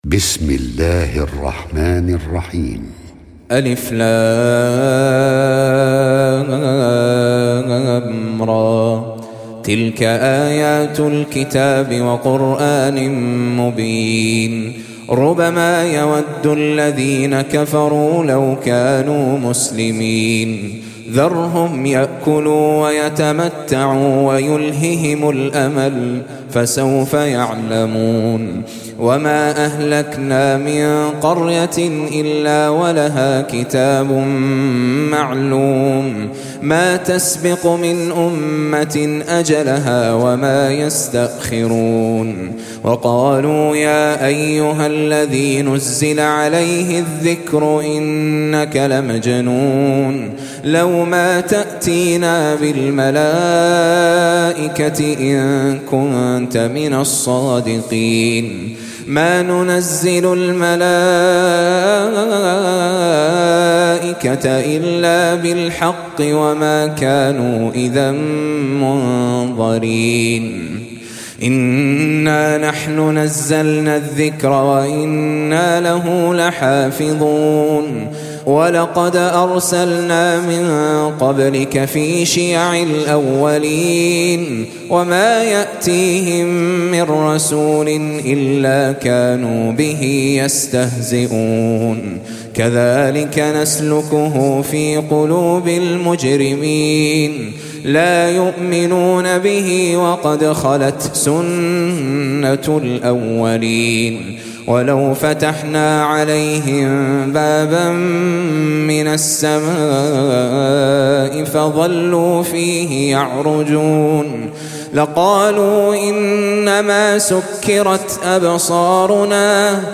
Surah Sequence تتابع السورة Download Surah حمّل السورة Reciting Murattalah Audio for 15. Surah Al-Hijr سورة الحجر N.B *Surah Includes Al-Basmalah Reciters Sequents تتابع التلاوات Reciters Repeats تكرار التلاوات